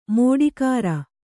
♪ mōḍikāra